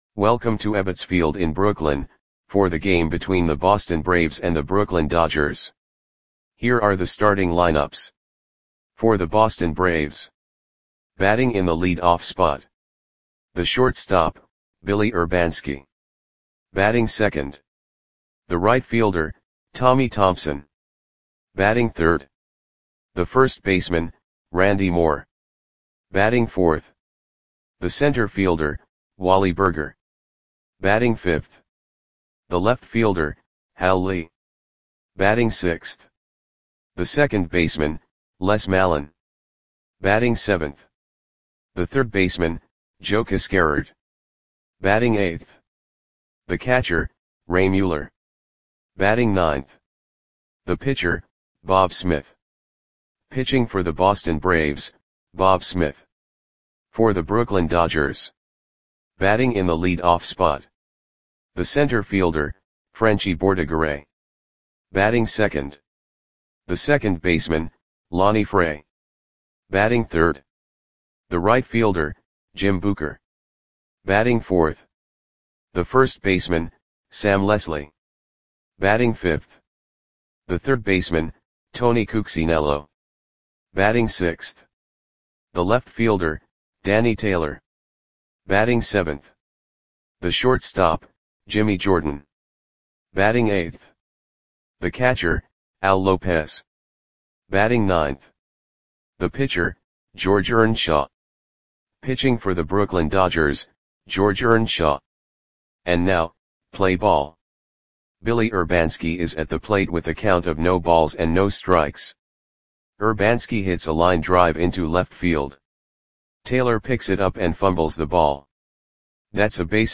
Audio Play-by-Play for Brooklyn Dodgers on August 11, 1935
Click the button below to listen to the audio play-by-play.